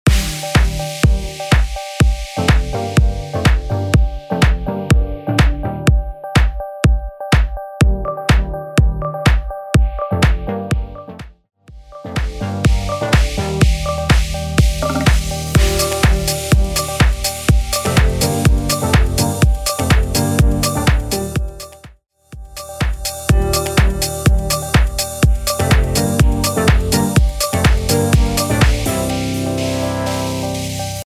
124 BPM
House
Upbeat Electronic